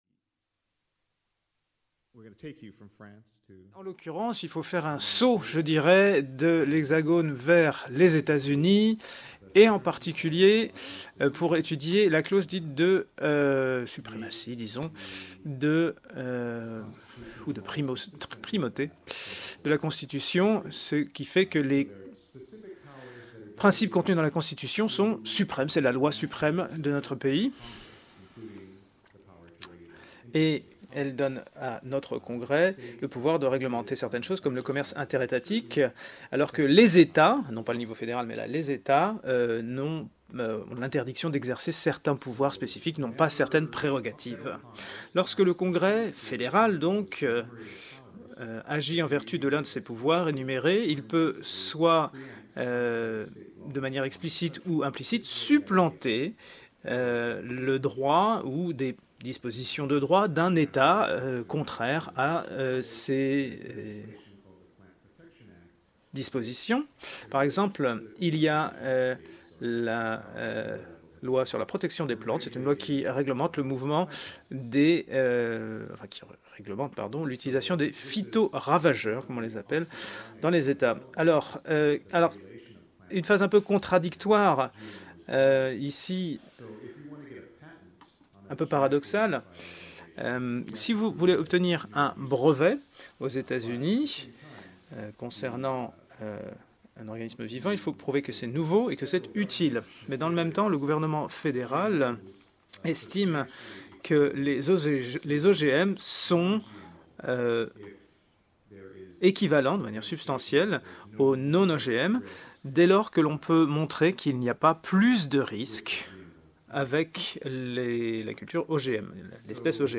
Cette communication a été prononcée dans le cadre du colloque international Agir en justice au nom des générations futures qui s'est tenu à Caen les 17 et 18 novembre 2017.